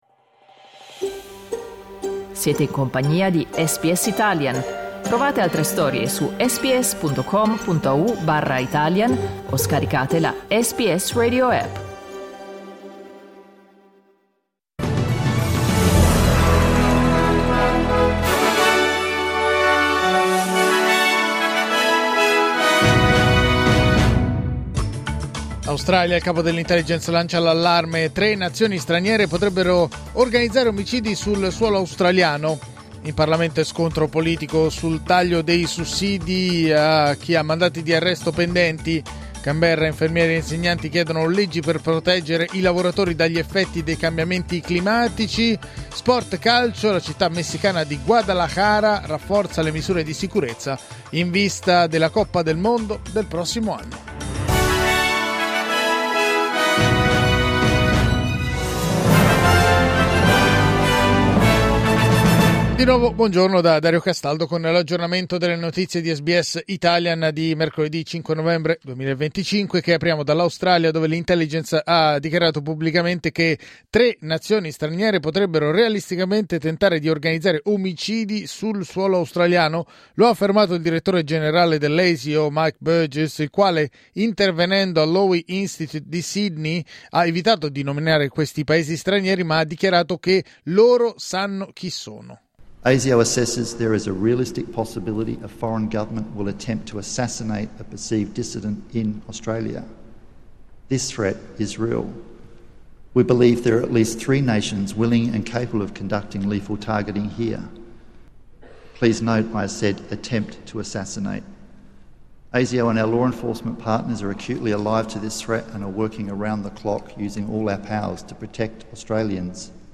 News flash mercoledì 5 novembre 2025
L’aggiornamento delle notizie di SBS Italian.